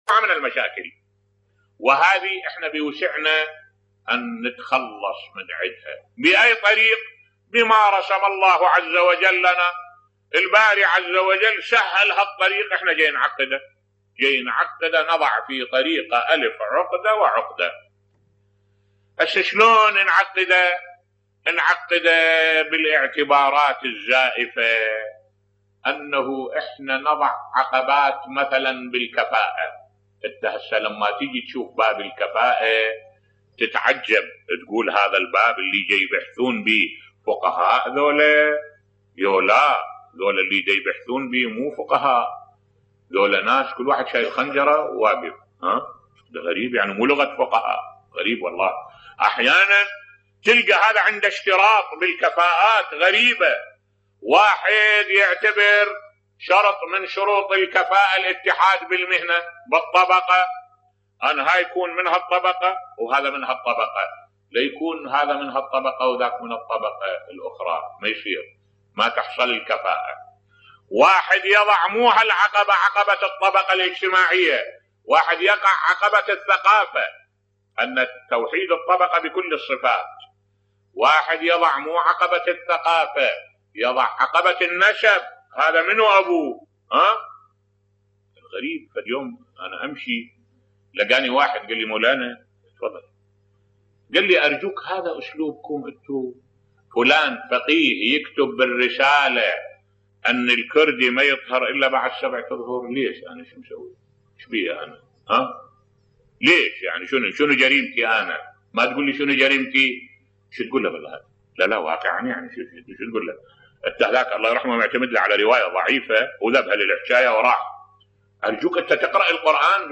ملف صوتی العنصرية في الزواج عند بعض فقهاء مسلمين بصوت الشيخ الدكتور أحمد الوائلي